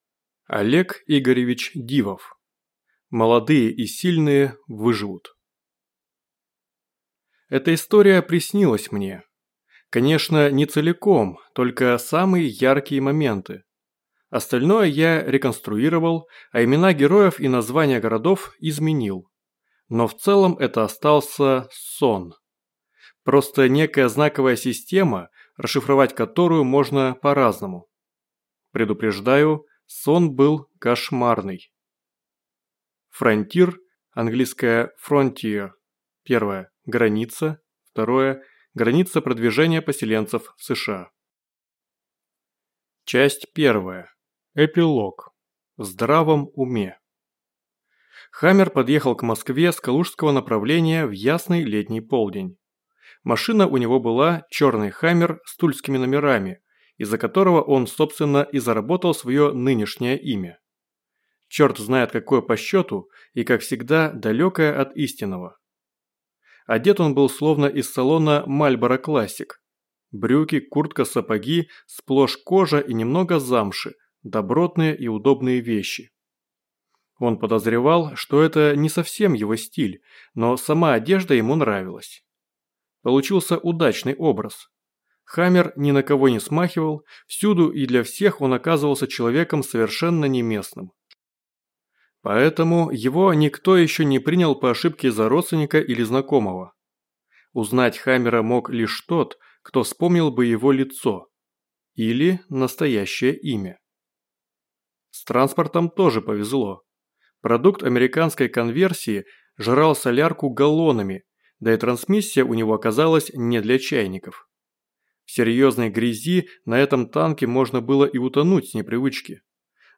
Аудиокнига Молодые и сильные выживут | Библиотека аудиокниг